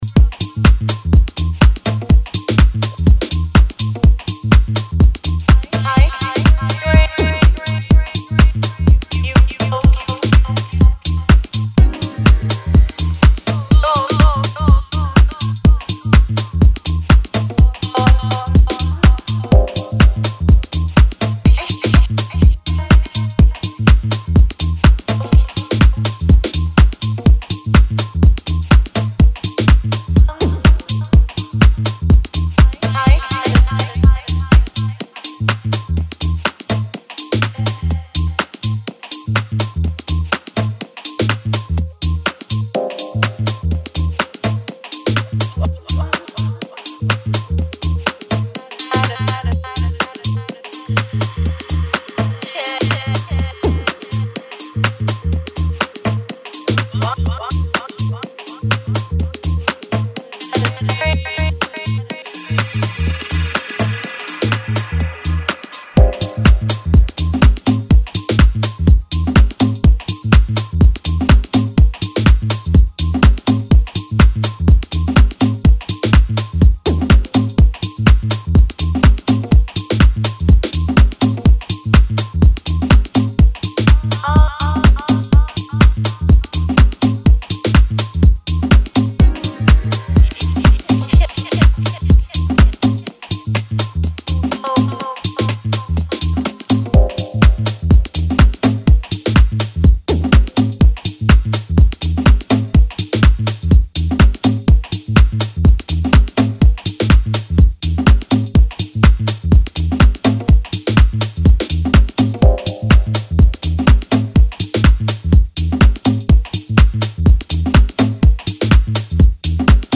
A funky minimal house track like no one else can do.